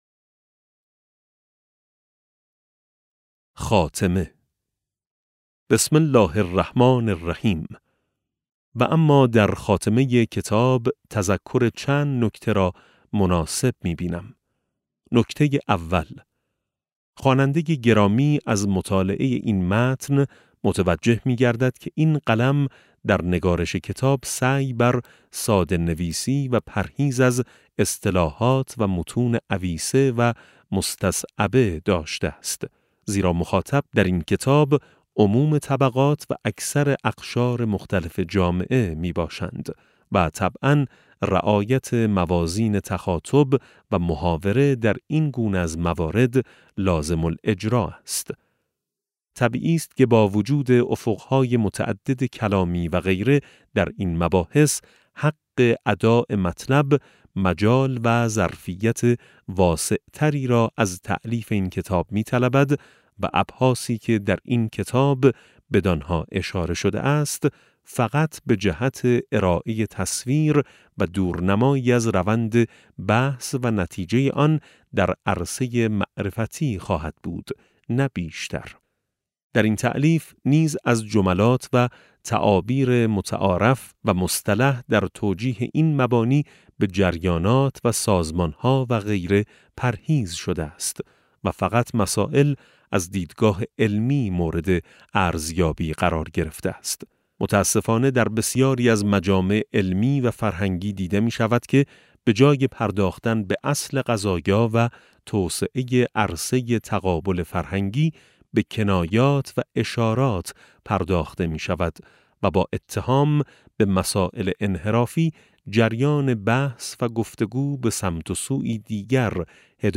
افق وحی - خاتمه (665 ـ 676) - کتاب صوتی - کتاب صوتی افق وحی - بخش37 - آیت‌ الله سید محمد محسن طهرانی | مکتب وحی